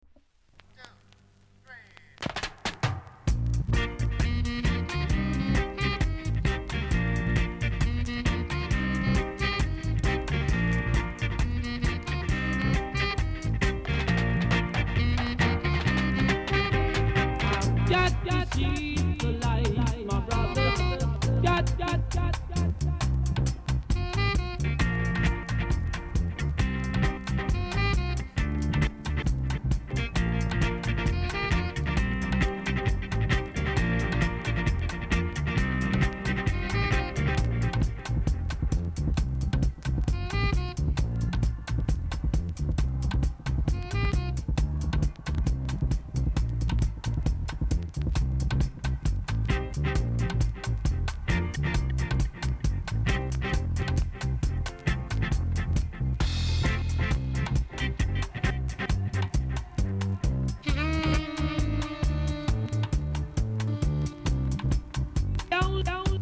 コメント NICE ROOTS!!